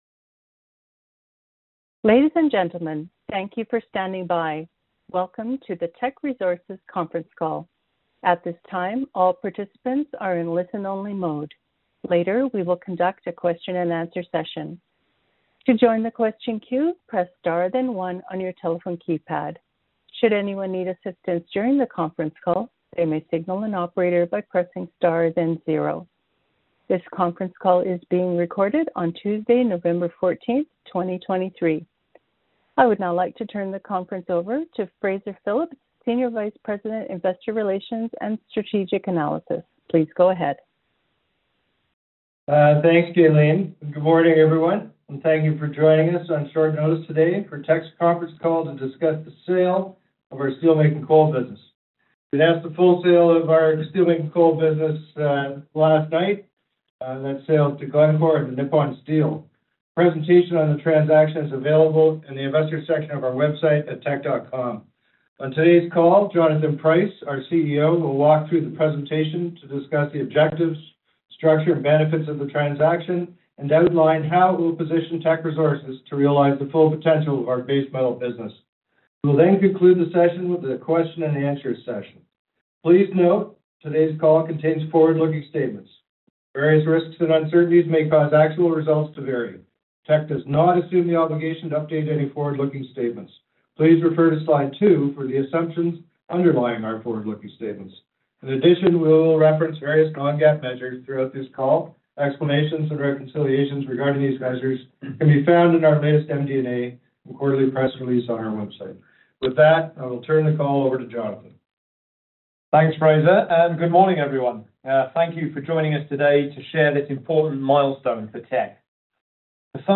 Teck-Conference-Call-November-14.mp3